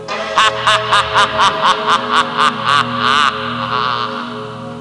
Download Cackling Intro sound effect for video, games and apps.
Cackling Intro Sound Effect
cackling-intro.mp3